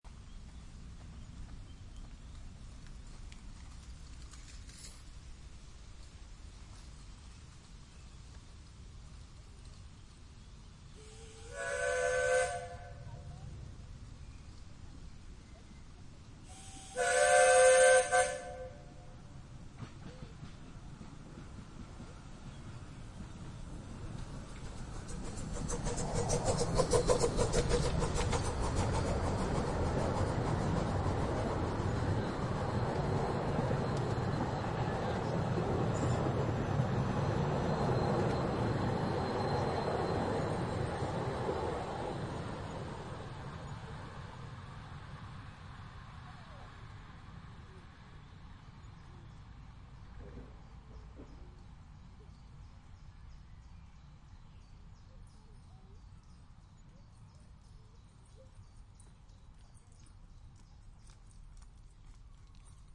Sound Effect  (best played while reading) courtesy of Freesound Community at Pixabay